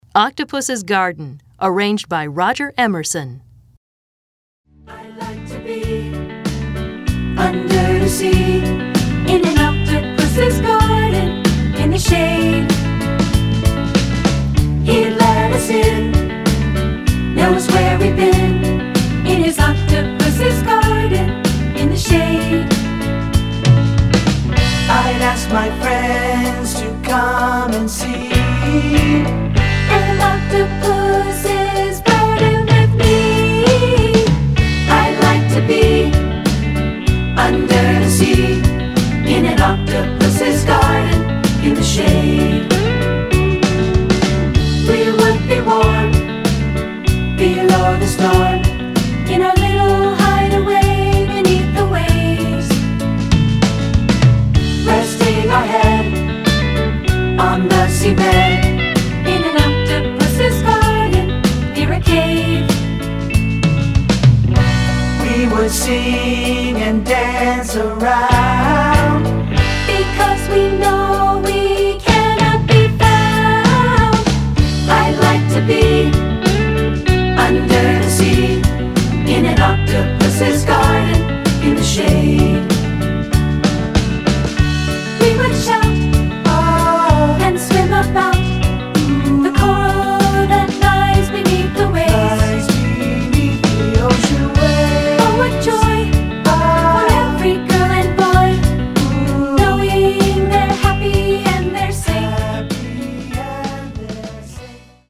Choral 50's and 60's Pop
This lighthearted song
3 Part Mix